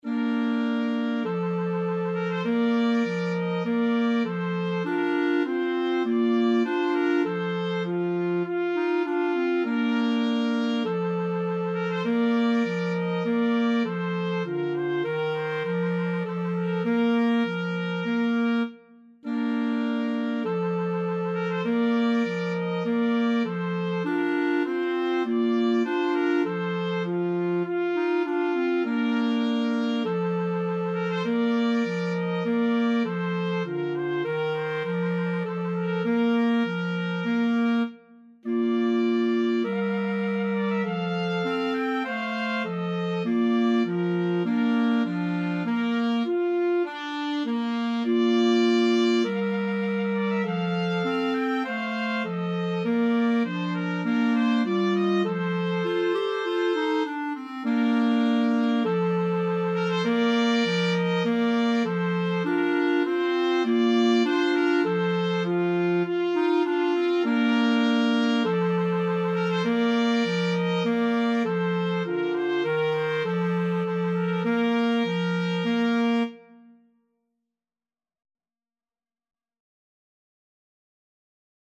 Free Sheet music for Flexible Mixed Ensemble - 3 Players
Trumpet
Alto Saxophone
Clarinet
Bb major (Sounding Pitch) (View more Bb major Music for Flexible Mixed Ensemble - 3 Players )
4/4 (View more 4/4 Music)
Andante
Classical (View more Classical Flexible Mixed Ensemble - 3 Players Music)